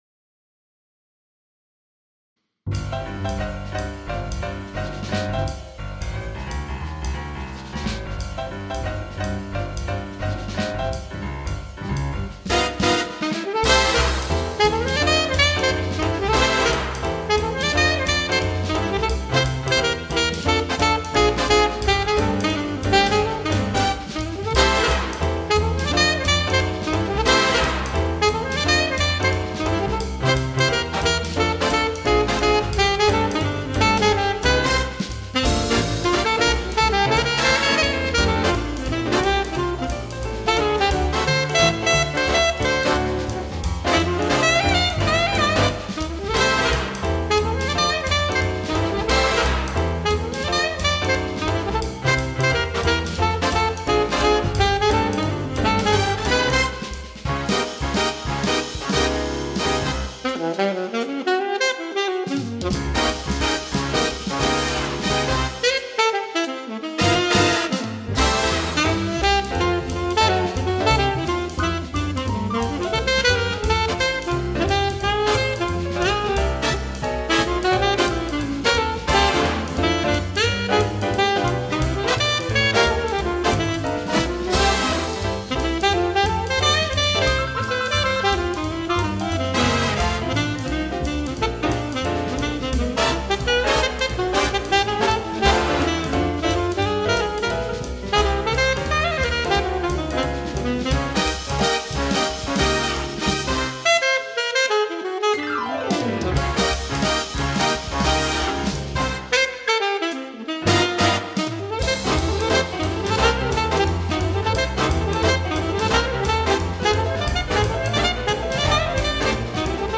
for alto saxophone & Piano